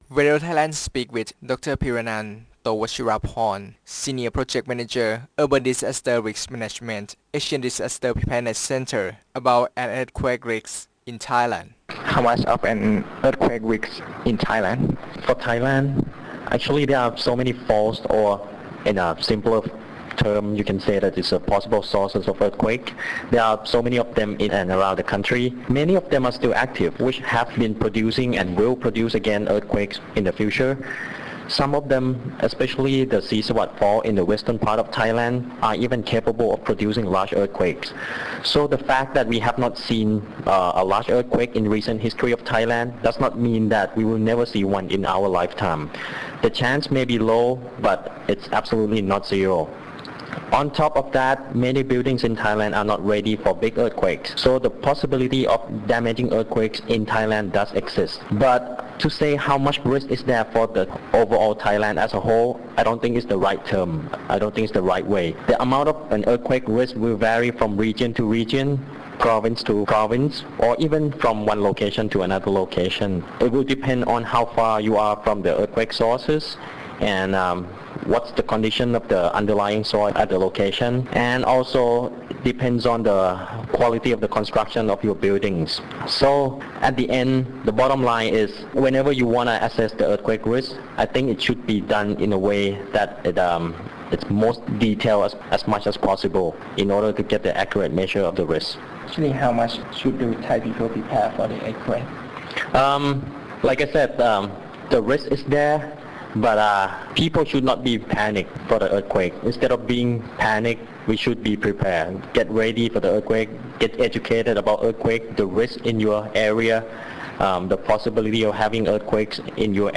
The recorded interviews have been aired through FM. 88
Interview_Radio Thailand_8June.mp3